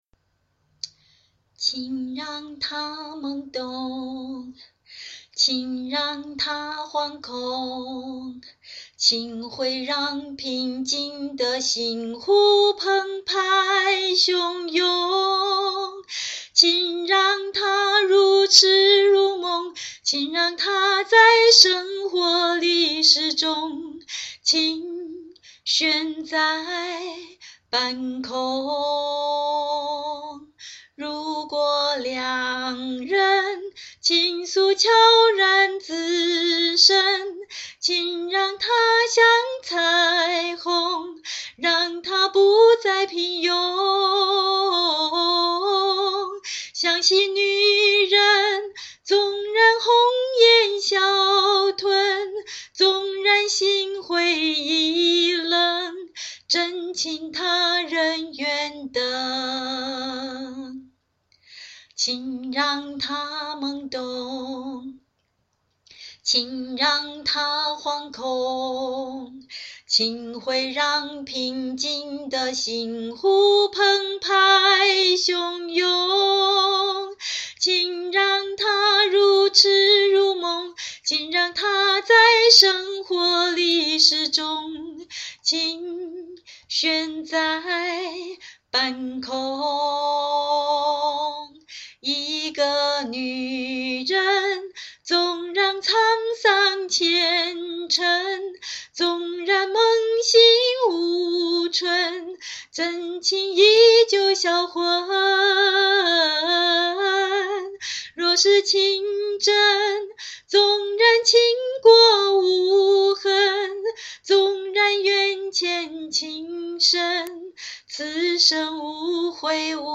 試唱 填詞 情